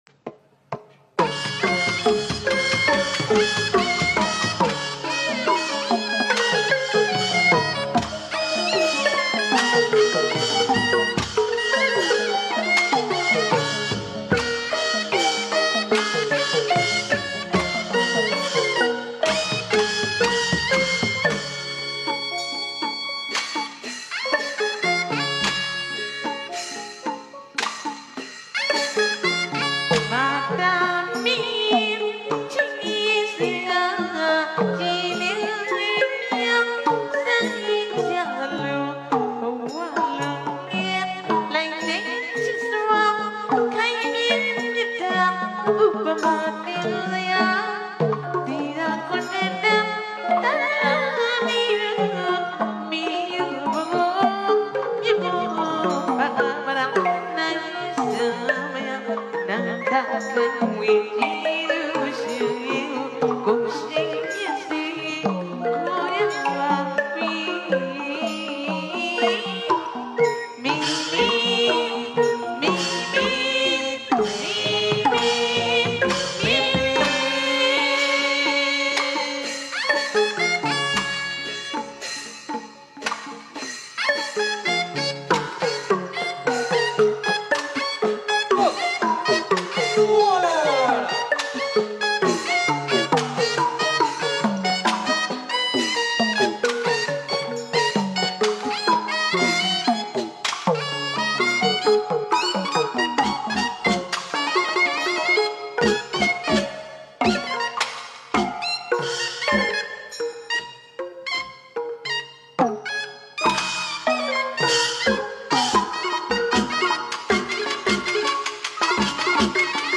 အဆိုအတီအရမ်းကောင်း